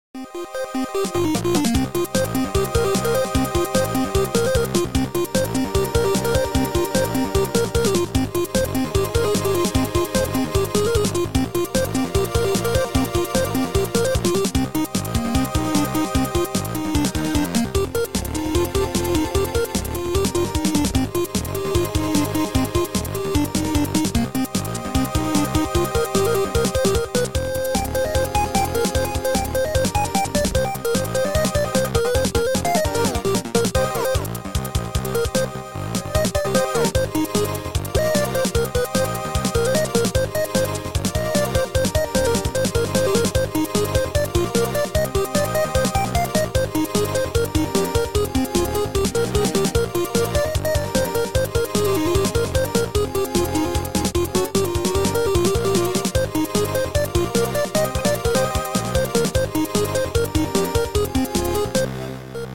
Sound Style: Chip